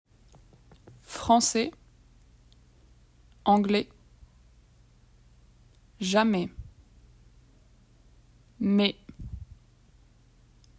Listen and repeat these common words in which the final -s is silent.